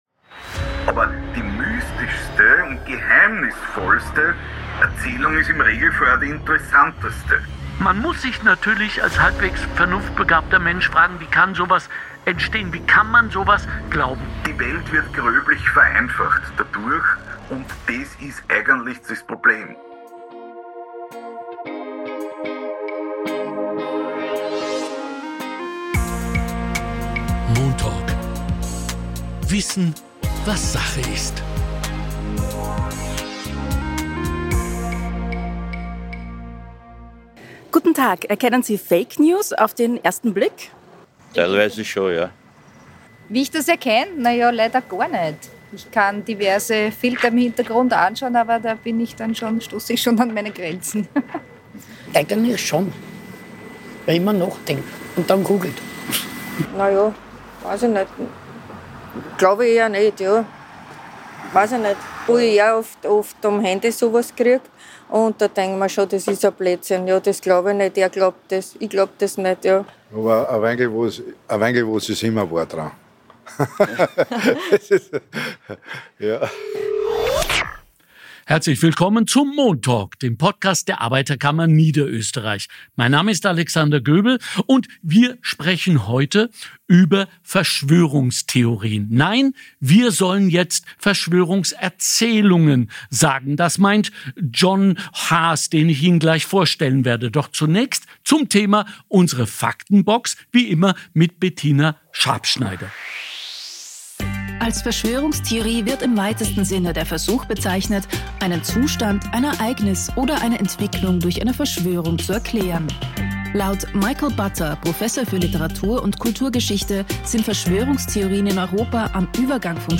Im Studio: Psychologe und Bestseller-Autor